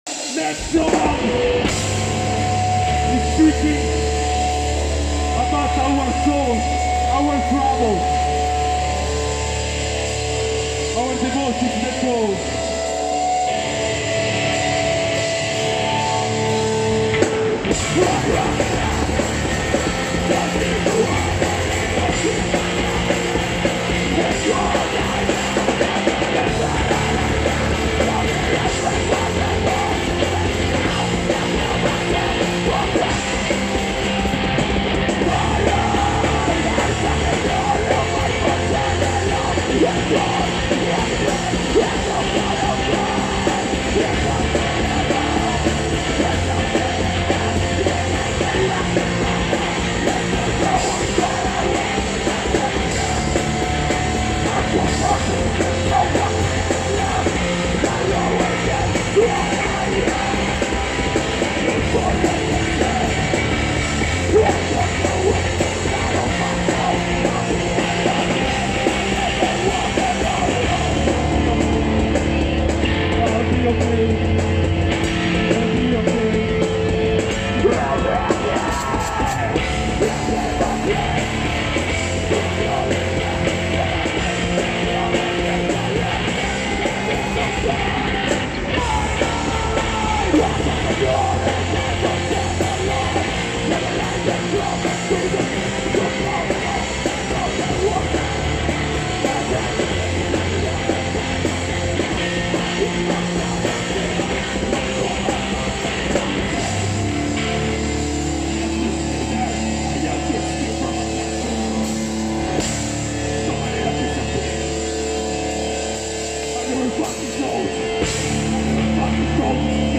(stage live audio):